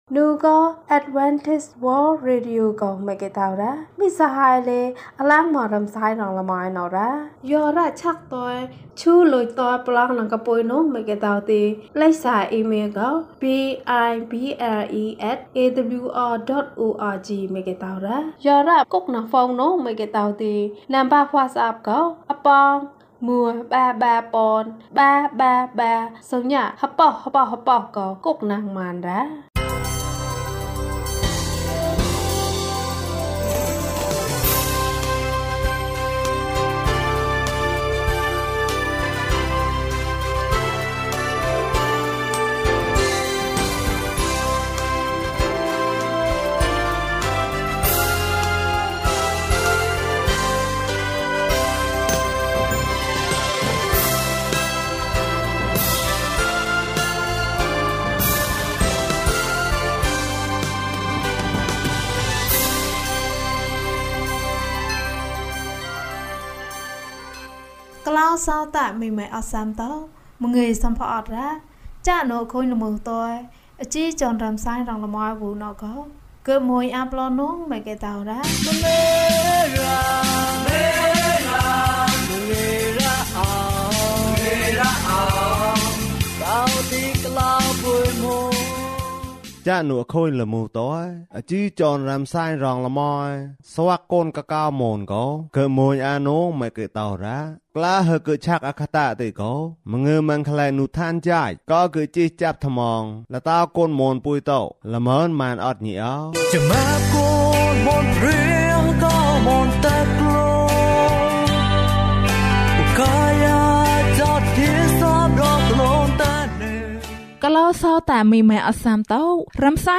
လူငယ်များအတွက် သတင်းစကား။၀၄ ကျန်းမာခြင်းအကြောင်းအရာ။ ဓမ္မသီချင်း။ တရားဒေသနာ။